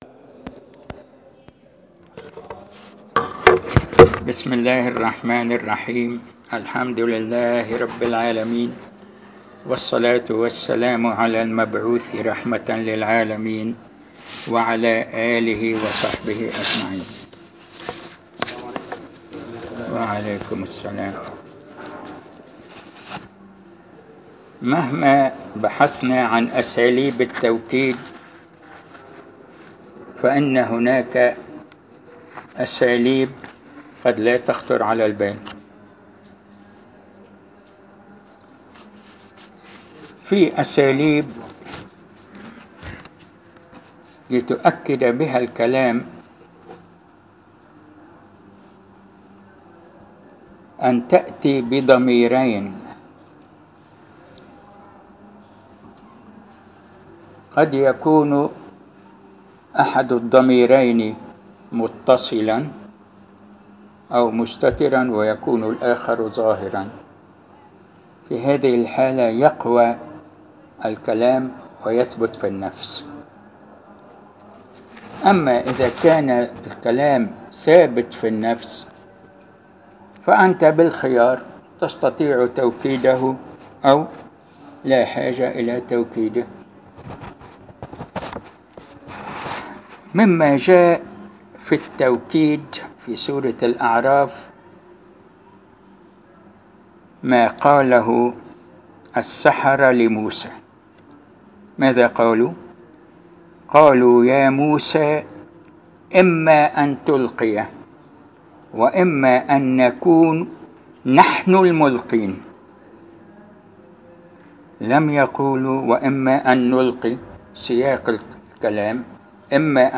المكان : المسجد البحري الموضوع : التوكيد عبر ضمير المنفصل والمتصل